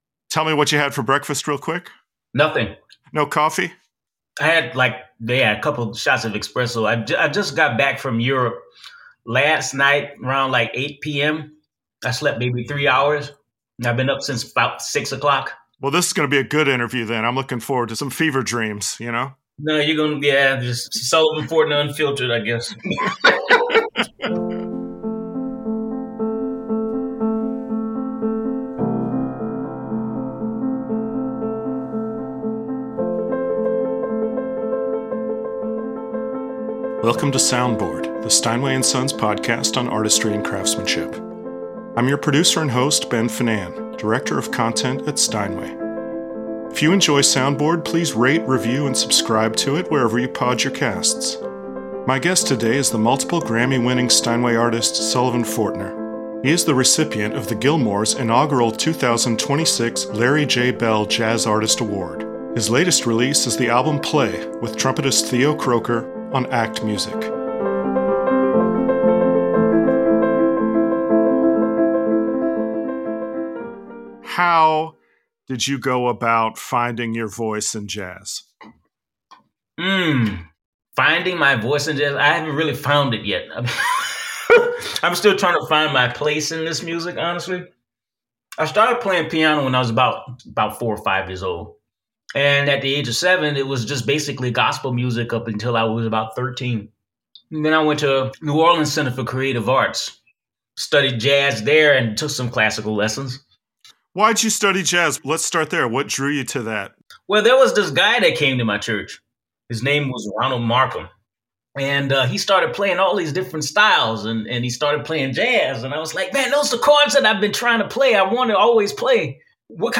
Be a guest on this podcast Language: en Genres: Arts , Music , Music Interviews , Performing Arts Contact email: Get it Feed URL: Get it iTunes ID: Get it Get all podcast data Listen Now...